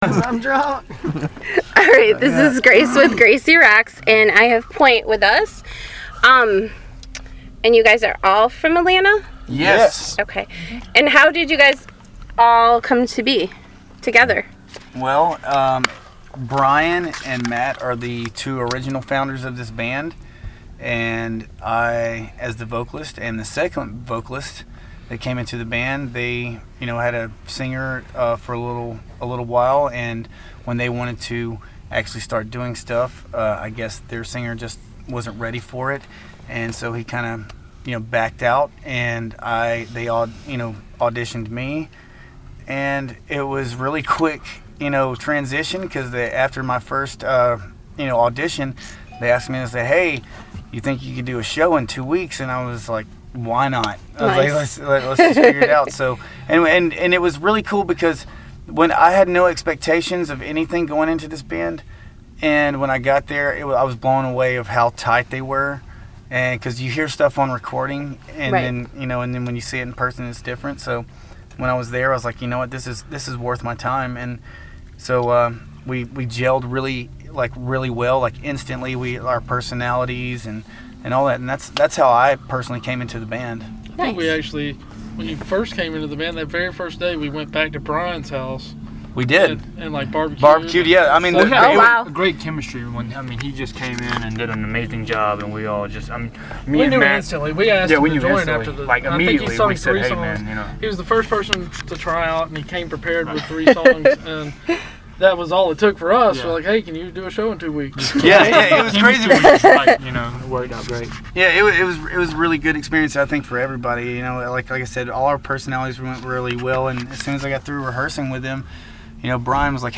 I had the pleasure of interviewing everyone in Poynte on April 15, 2016, before they took the stage at The Ritz Detroit!